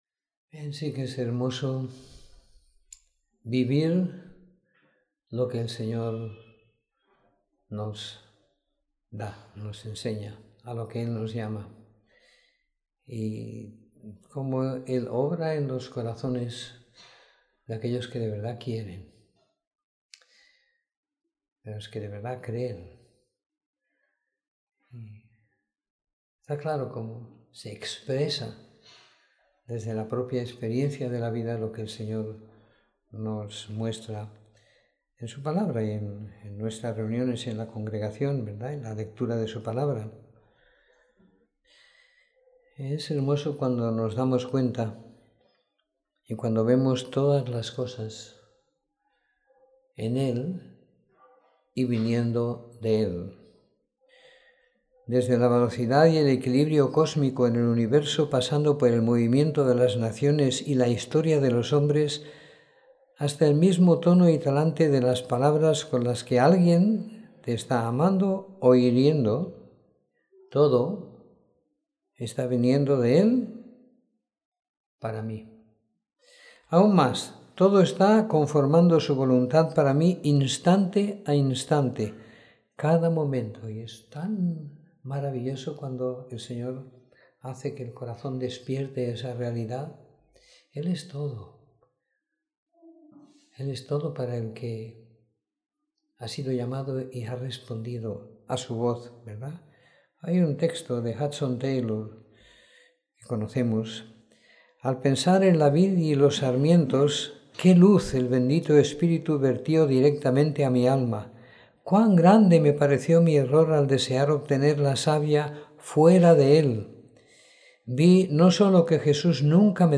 Domingo por la Tarde . 03 de Julio de 2016